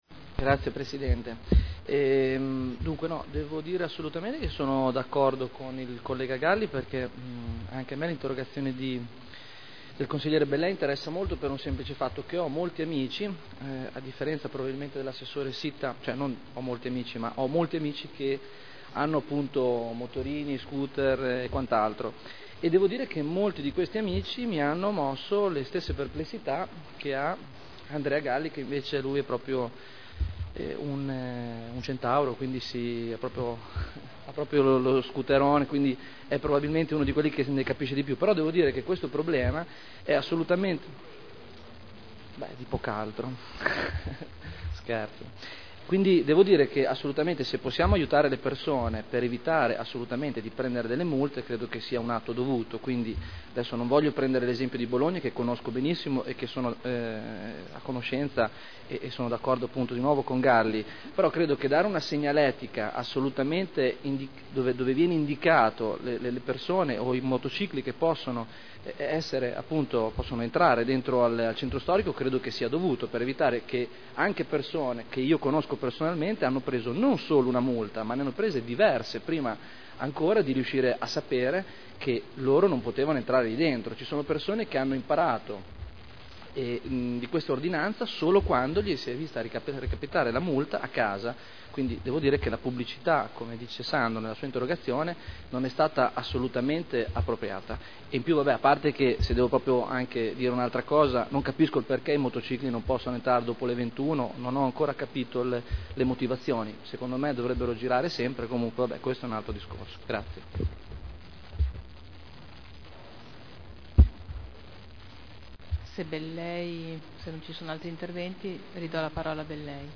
Barberini — Sito Audio Consiglio Comunale
Seduta del 09/11/2009. Confusione nell'accesso dei motocicli alla zona ZTL